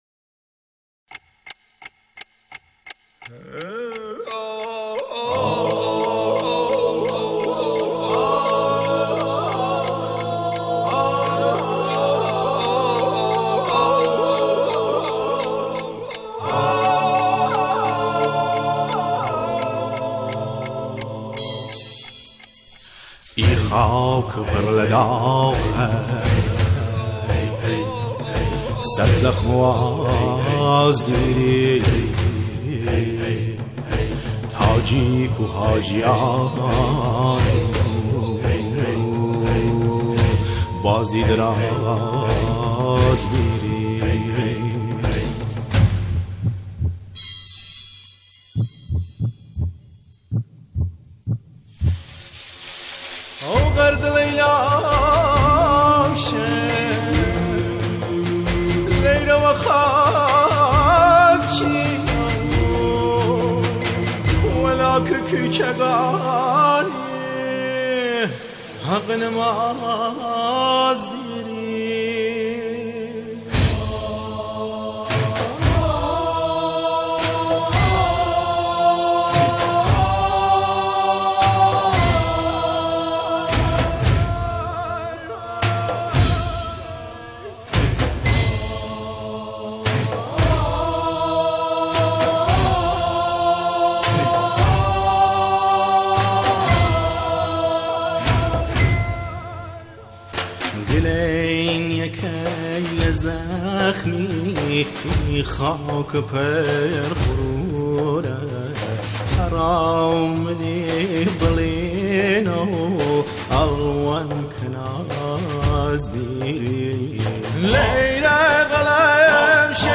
سرودهای شهدا